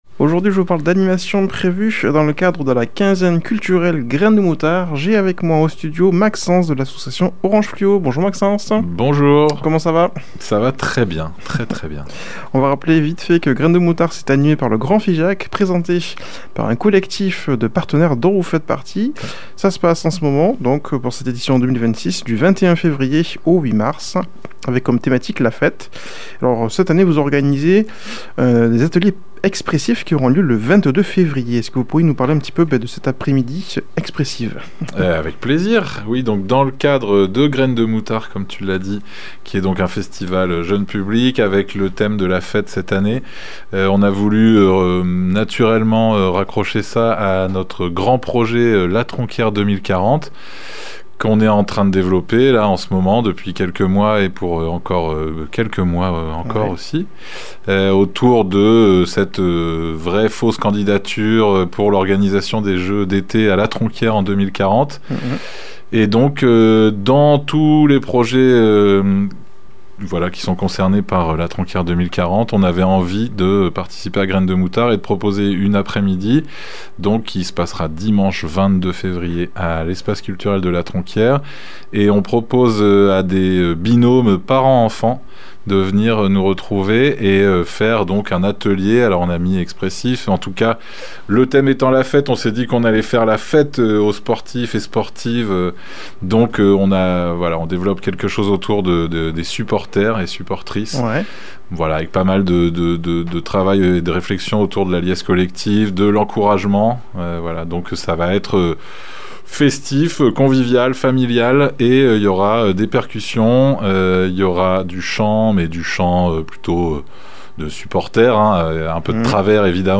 Présentateur